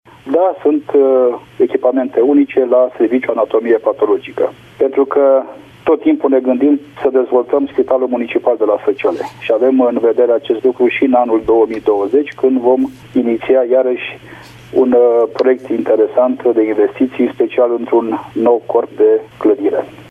De cele mai multe ori, pacienții sunt nevoiți să apeleze la servicii de medicină privată, contra-cost, în centre medicale din București, Cluj-Napoca sau Târgu Mureș, mai spune primarul:
Primar-Virgil-Popa-spital-Sacele-2.mp3